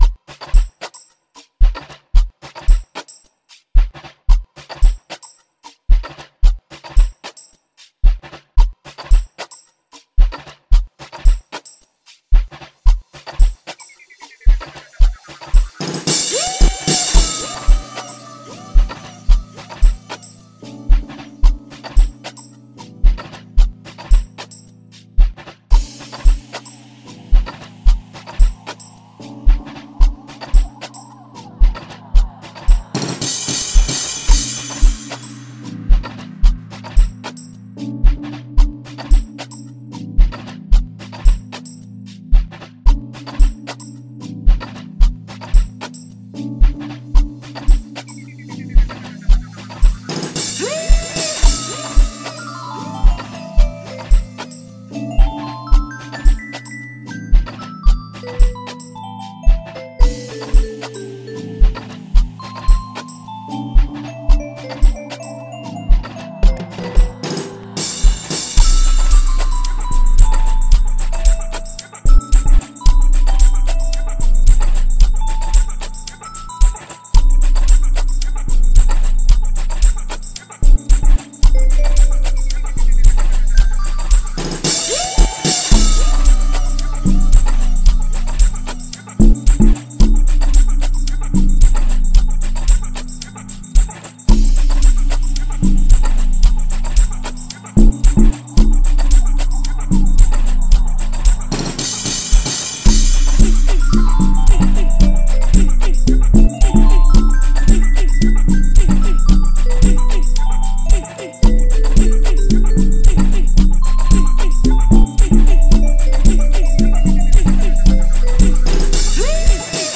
06:51 Genre : Amapiano Size